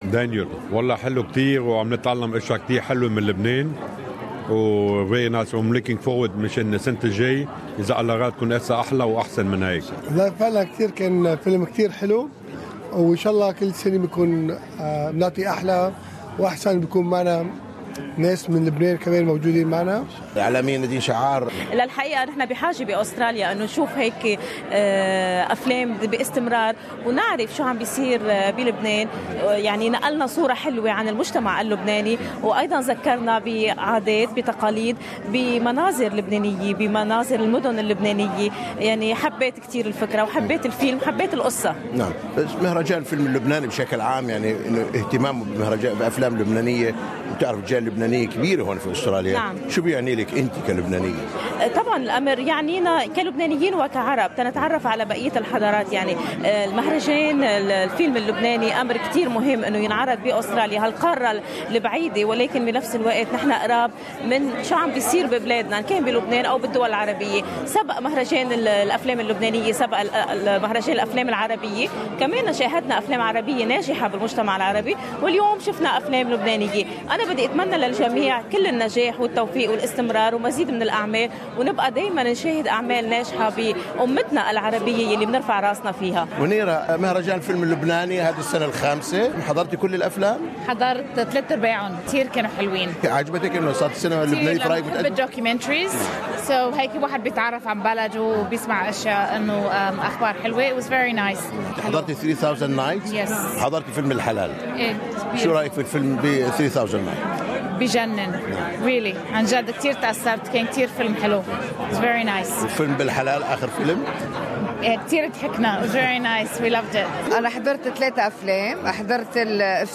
واستطلع اراء الحضور بافلام المهرجان .